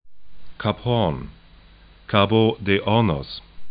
kap 'ho:ɐn
'ka:bo de 'ɔrnɔs